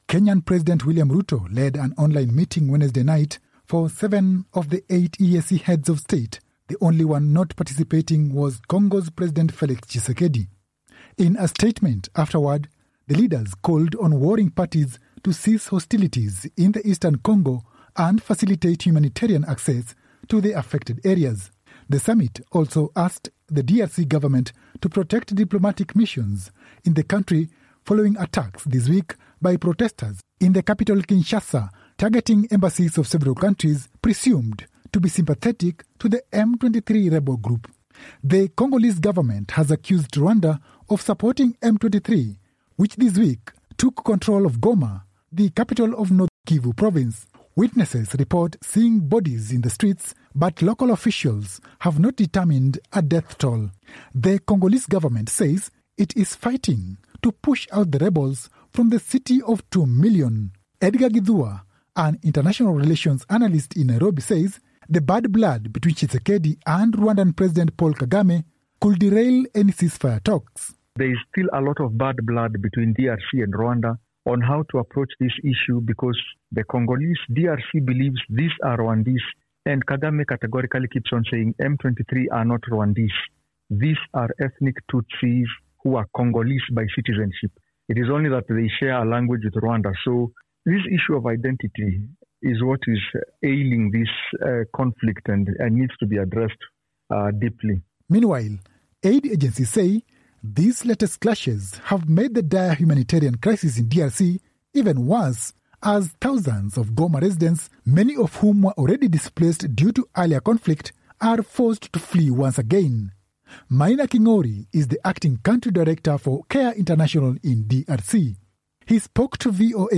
reports from Nairobi.